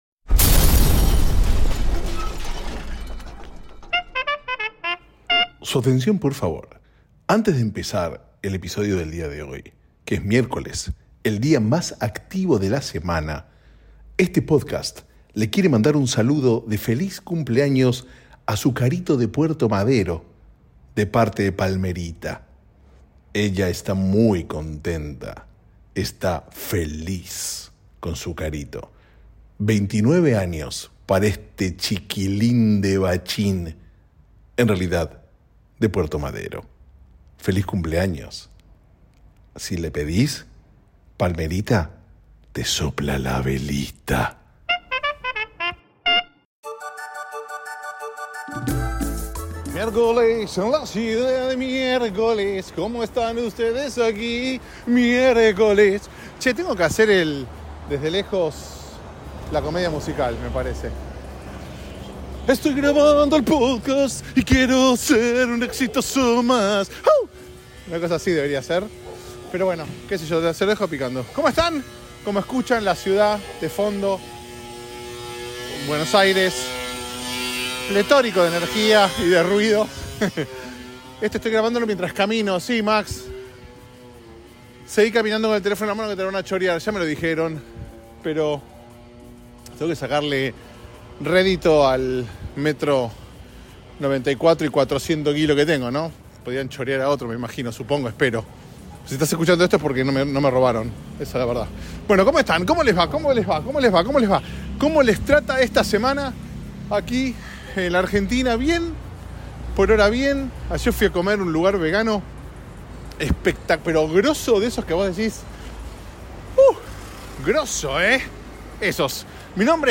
Grabado en las mismísimas calles de Buenos Aires. Incluye un saludo de cumpleaños pues... me debo a mis fans!